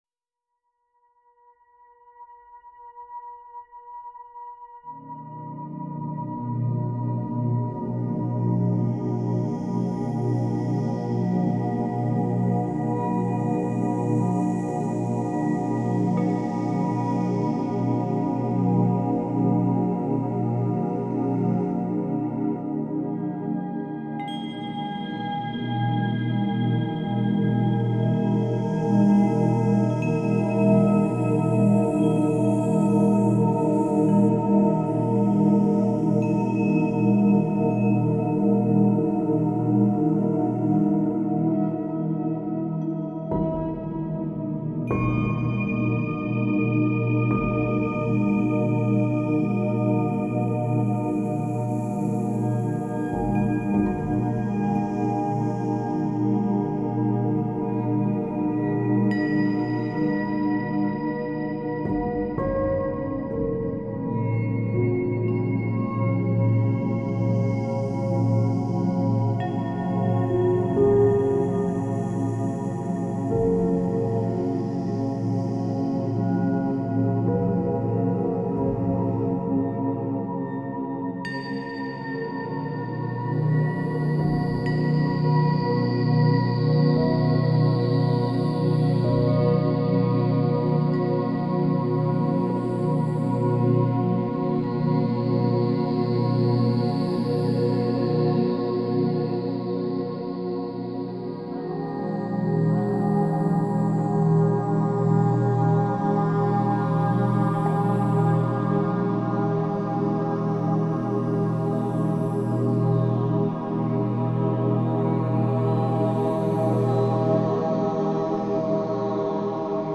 Catégorie : Musique de relaxation